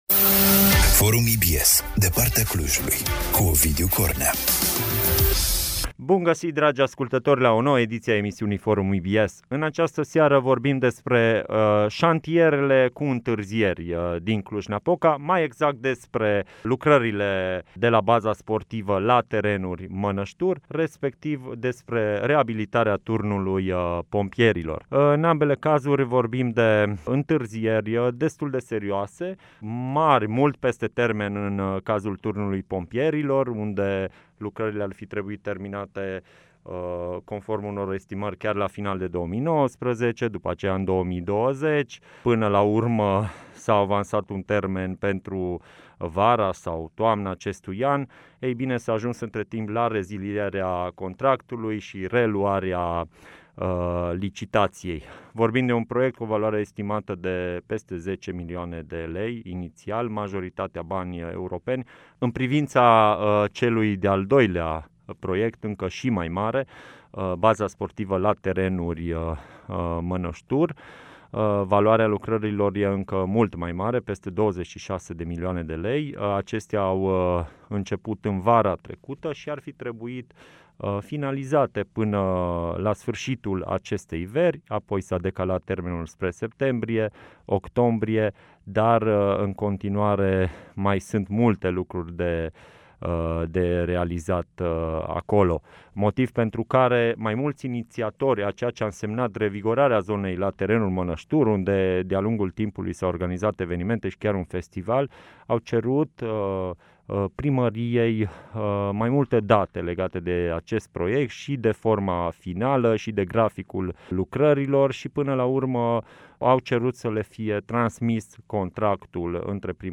Despre întârzierile de pe două două dintre cele mai importante șantiere din Cluj-Napoca am discutat la cea mai recentă ediție a emisiunii Forum EBS. Este vorba despre baza sportivă la Terenuri, al cărei termen de finalizare ar putea fi depășit cu aproximativ un an și de Turnul Pompierilor, cu o întârziere încă și mai mare.